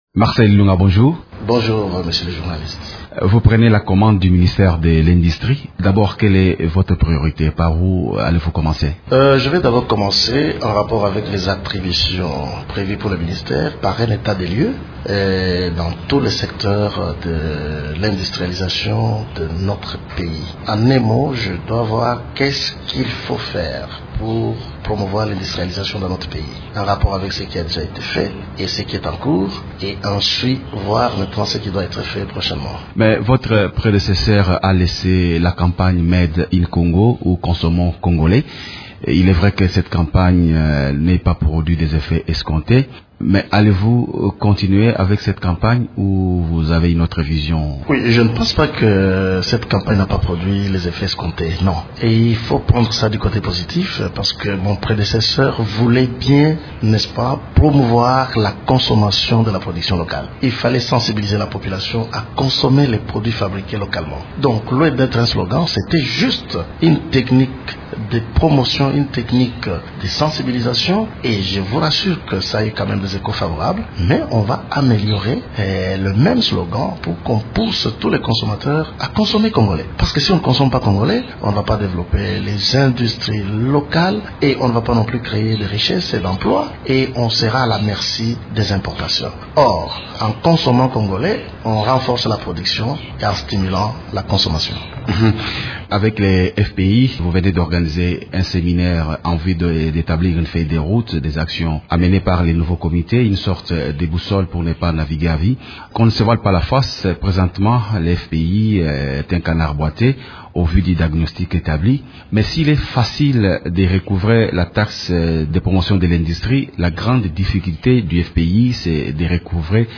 Invité de Radio Okapi lundi 2 janvier, le nouveau ministre de l’Industrie, Marcel Ilunga, promet de faire voter la loi sur les privilèges du trésor pour recouvrer les créances du Fonds de promotion de l’industrie (FPI).
invite_ministre_de_lindustrie_marcel_ilunga_lewu-00.mp3